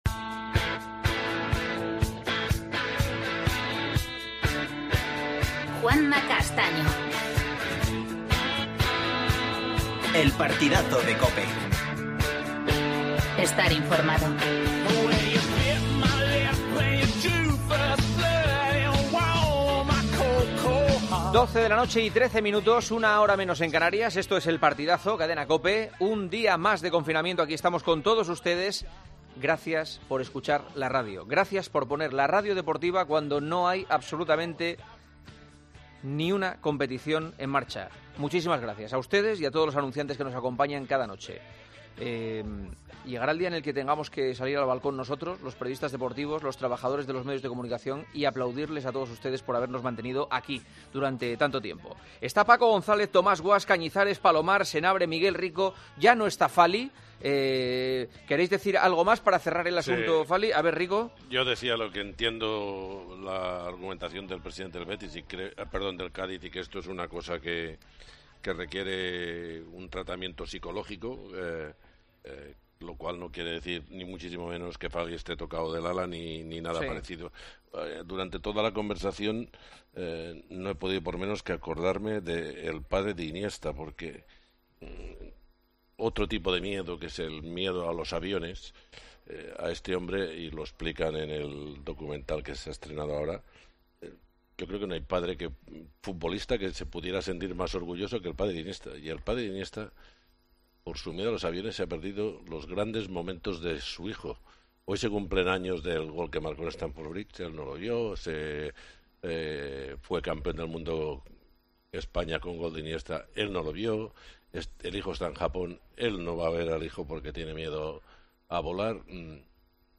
Entrevista al jugador del Eintracht Lucas Torró. El fútbol en Italia. Actualidad del Real Madrid y Atleti.